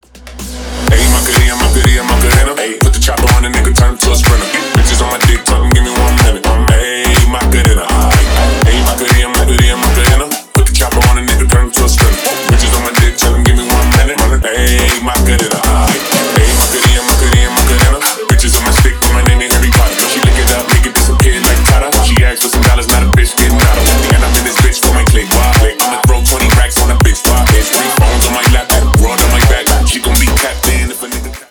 Танцевальные
клубные # громкие # кавер